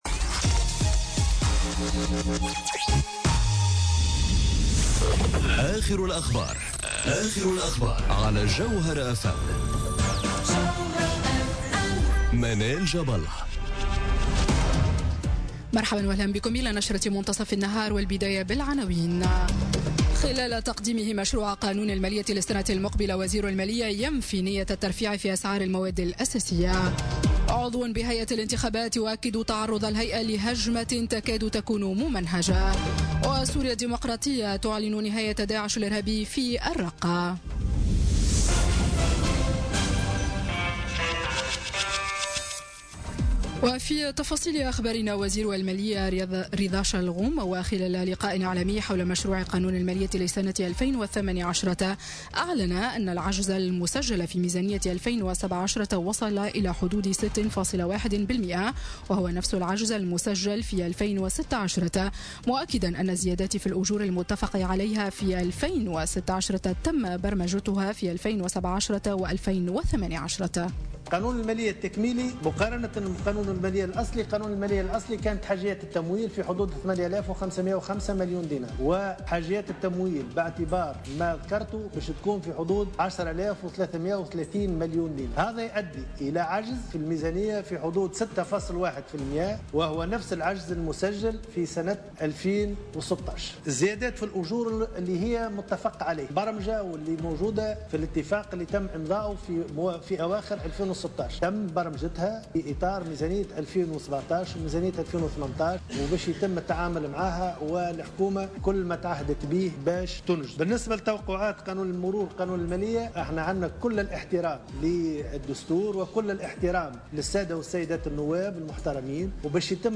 نشرة أخبار منتصف النهار ليوم الثلاثاء 17 أكتوبر 2017